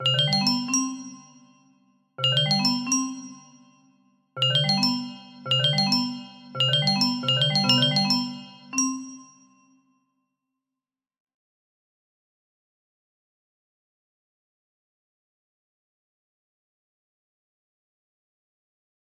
A Free to Use Gameshow or intermission theme.